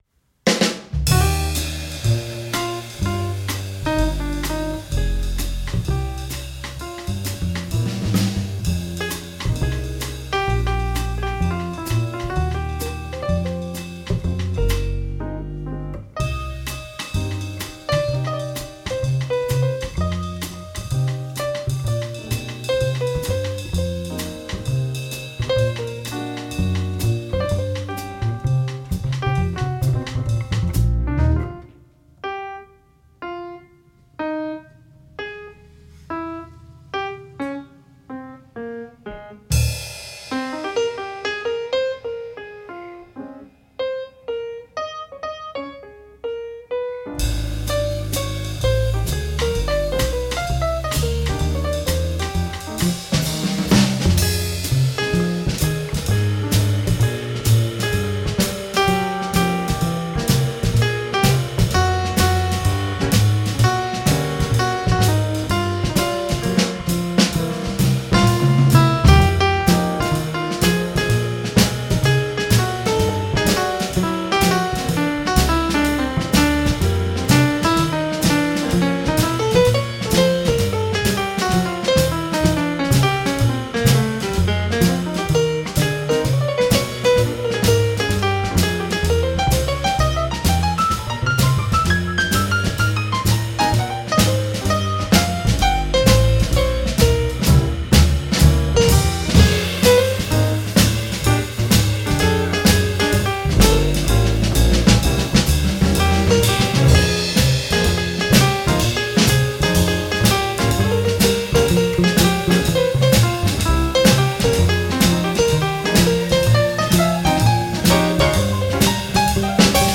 Keys
Drums
Bass